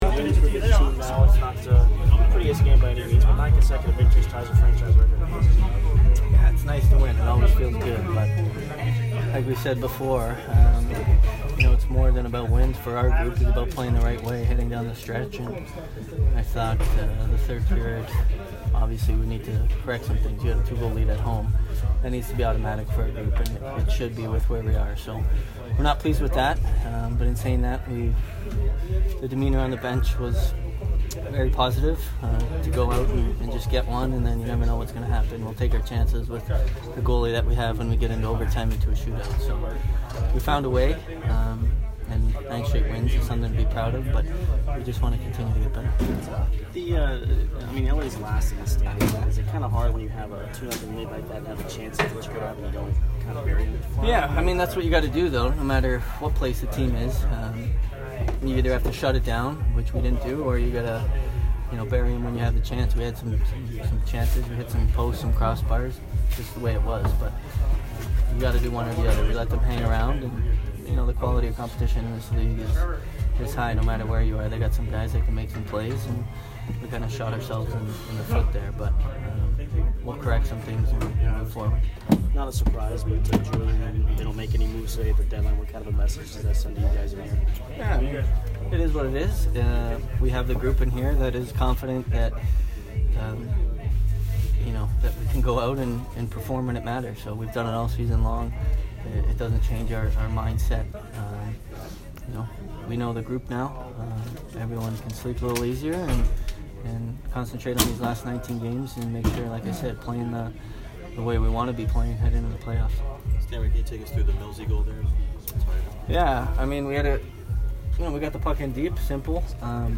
Steven Stamkos post-game 2/25